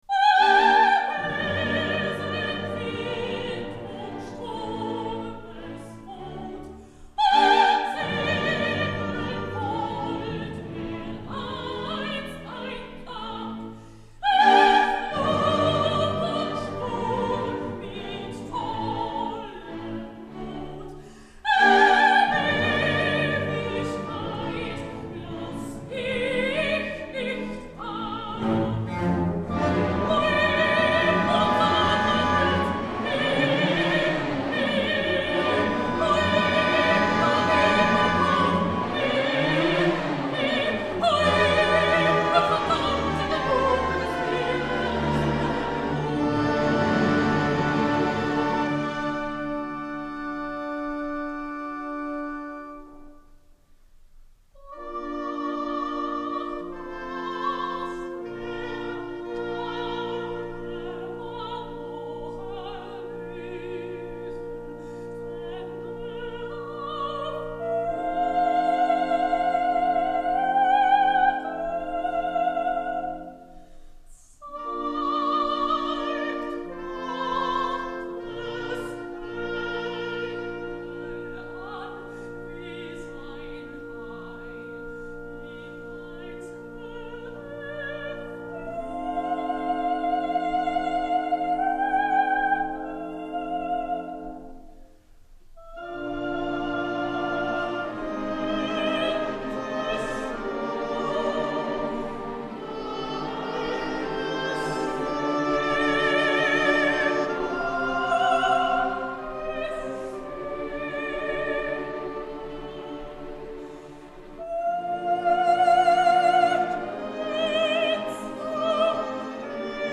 Die zweite Strophe der 'Ballade vom Fliegenden Holländer', gesungen von Anja Silja (EMI Records Ltd., 1968).